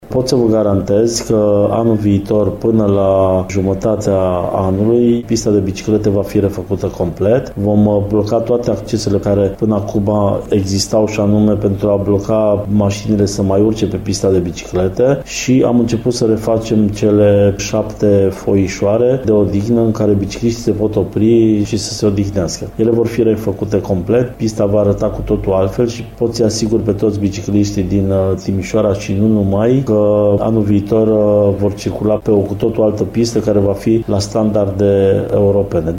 Administrația județeană se va ocupa de refacerea traseului, iar lucrările vor dura până la jumătatea anului viitor, spune vicepreședintele CJ Timiș, Alin Popoviciu.